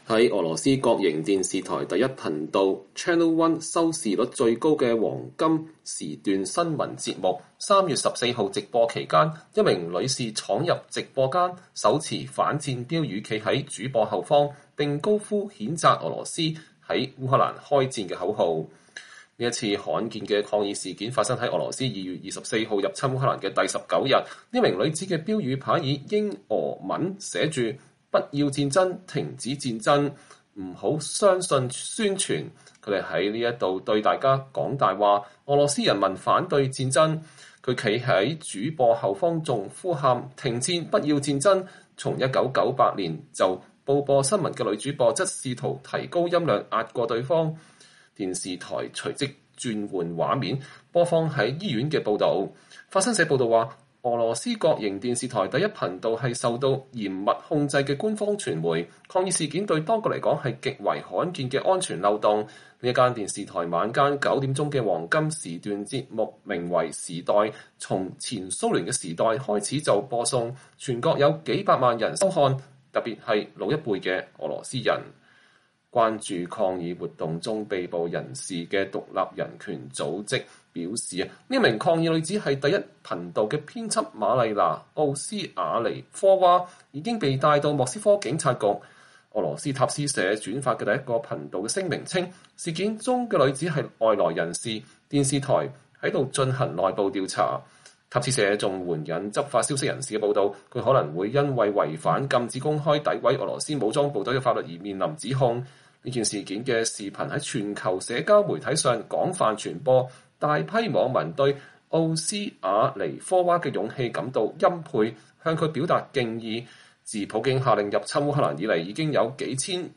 在俄羅斯國營電視台第一頻道（Channel One）收視率最高的黃金檔新聞節目3月14日直播期間，一名女士闖入直播間，手持反戰標語站在主播後方，並高呼譴責俄羅斯在烏克蘭開戰的口號。